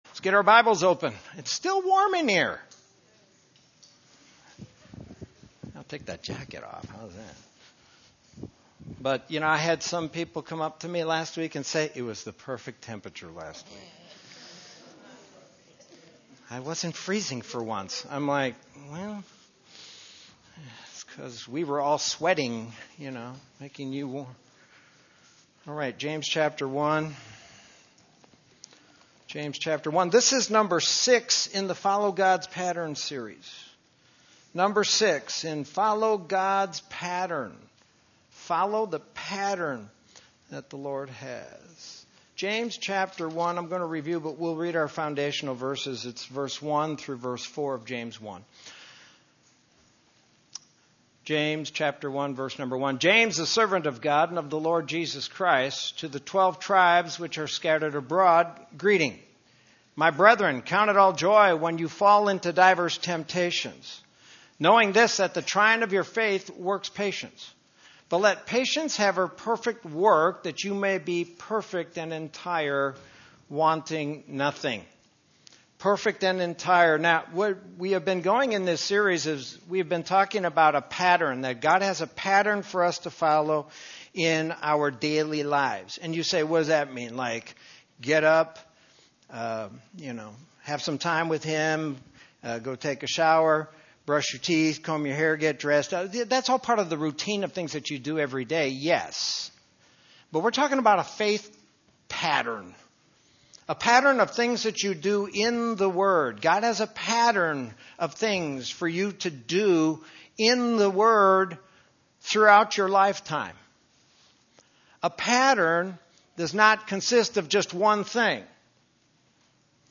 Sermon from September 15, 2019.